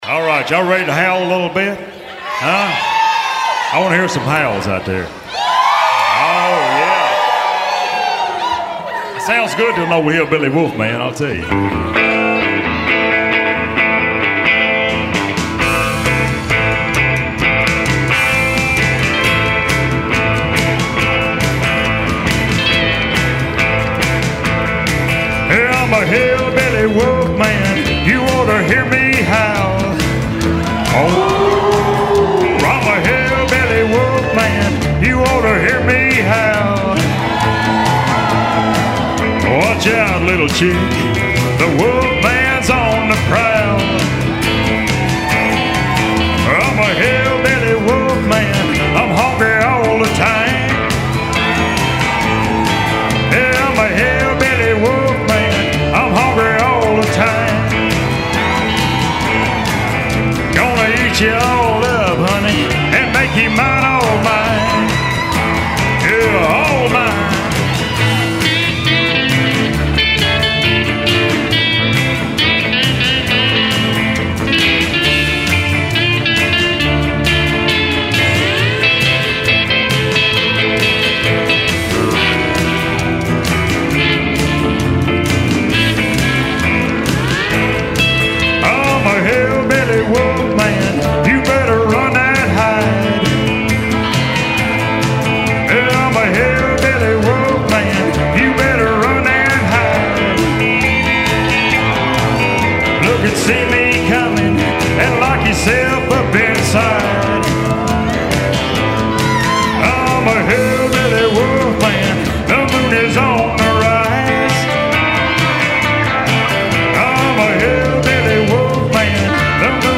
TWANGY COUNTRY AT IT'S BEST  !